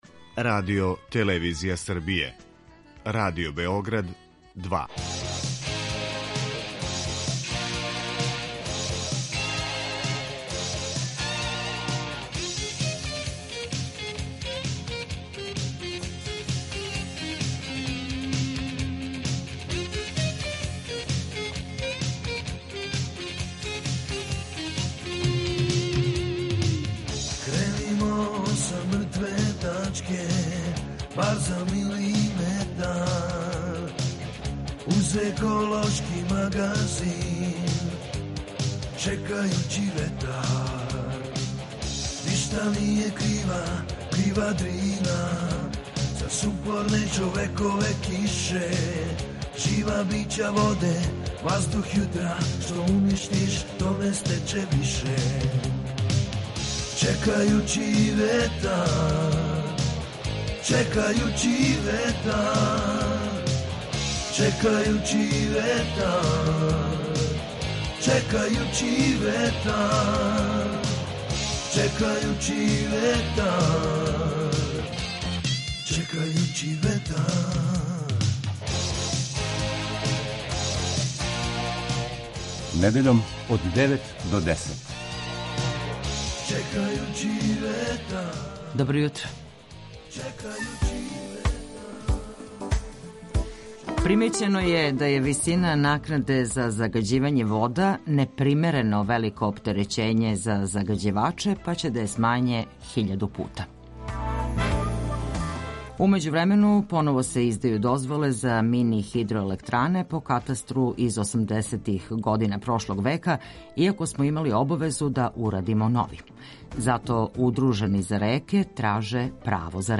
ОВДЕ Чекајући ветар - еколошки магазин Радио Београда 2 који се бави односом човека и животне средине, човека и природе.